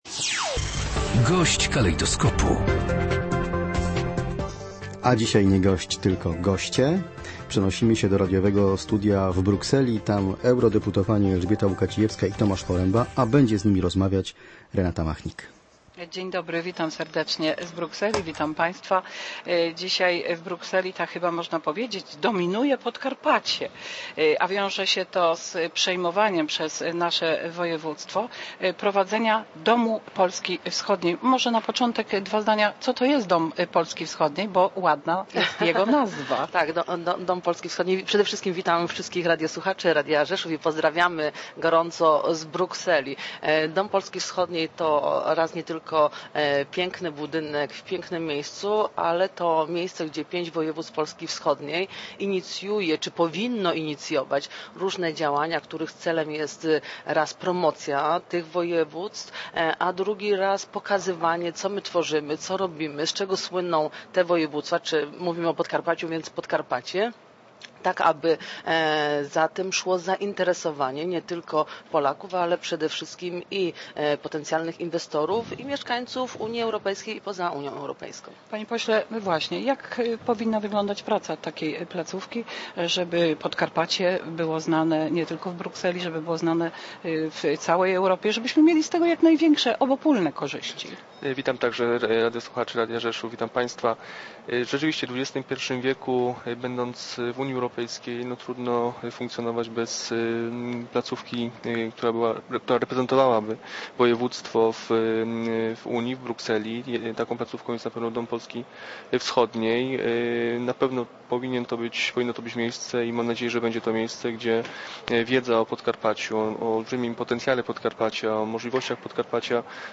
W dniu 24 stycznia 2012 r. Elżbieta Łukacijewska i Tomasz Poręba, Posłowie do Parlamentu Europejskiego z Województwa Podkarpackiego, byli „Gośćmi Dnia" programu „Kalejdoskop" w Polskim Radiu Rzeszów.